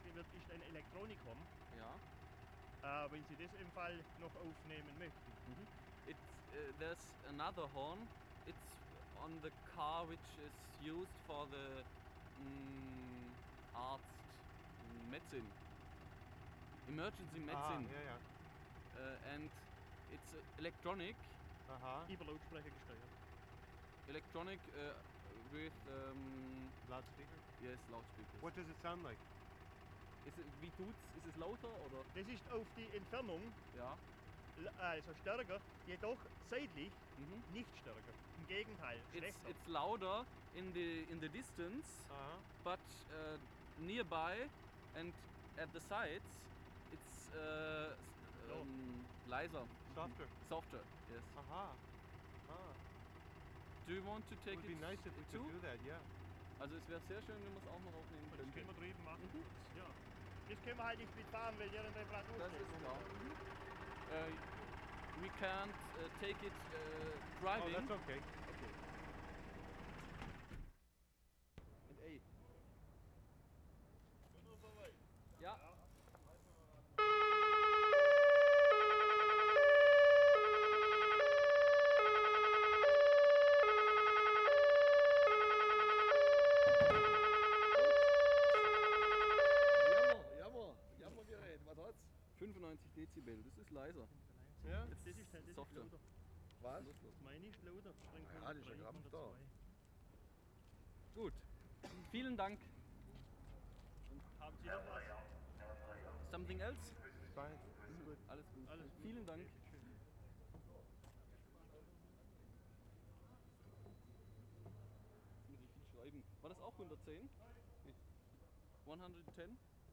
WORLD SOUNDSCAPE PROJECT TAPE LIBRARY
Stuttgart, Germany Feb. 27/75
NEW EMERGENCY SIREN
8. Siren (as explained in 7) has a warble on lower note; it is electronic, directed over loudspeakers.